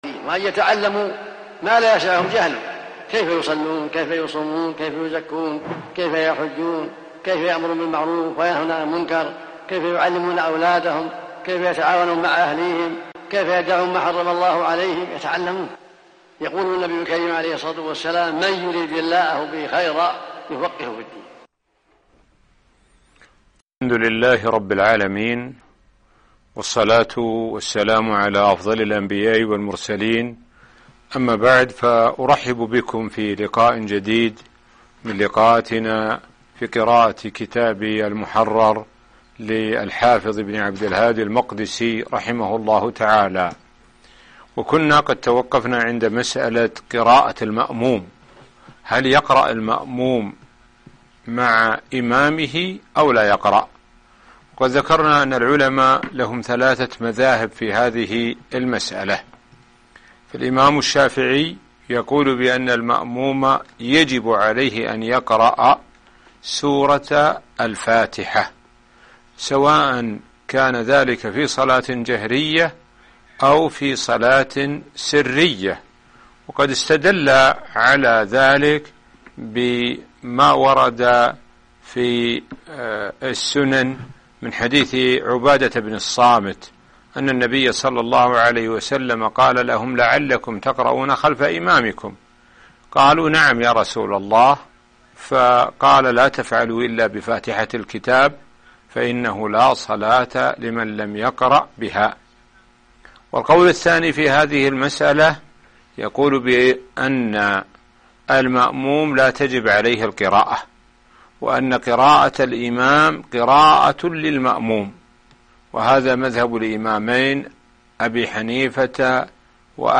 الدرس 17 (المحرر في الحديث البناء العلمي) - الشيخ سعد بن ناصر الشثري